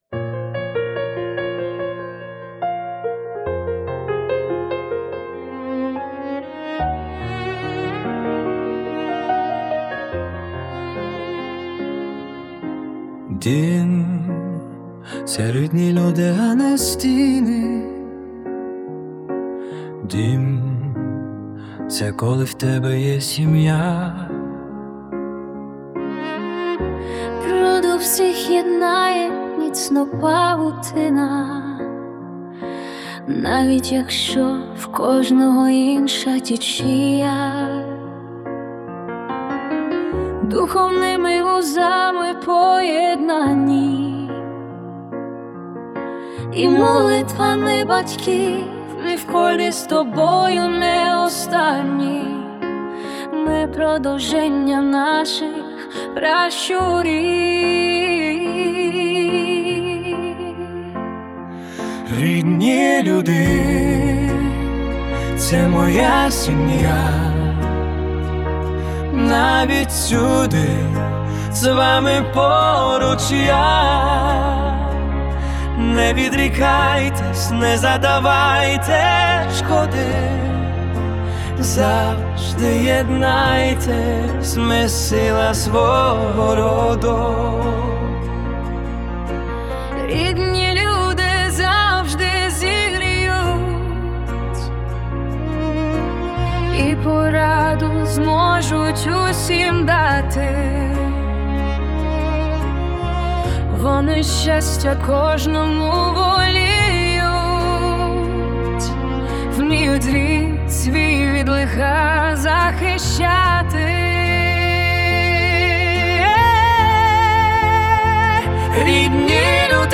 ТИП: Пісня